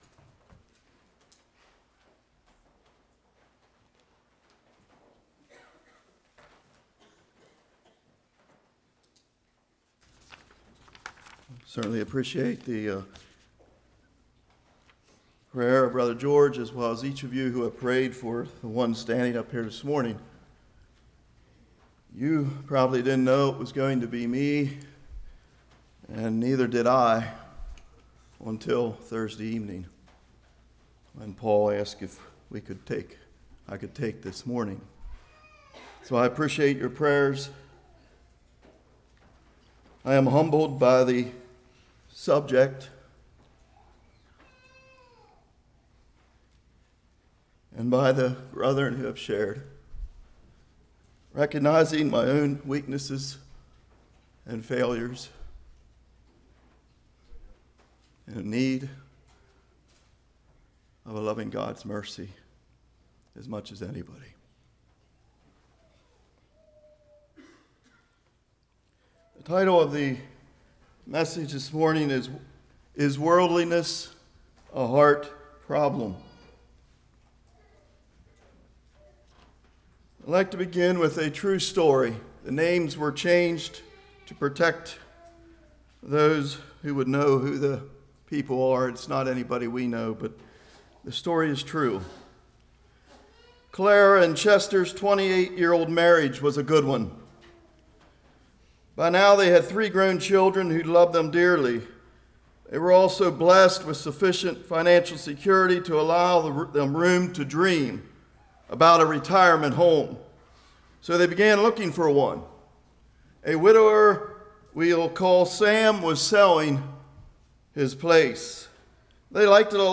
James 4:1-10 Service Type: Morning Spiritual Adultery Enemy of God It Is A Choice?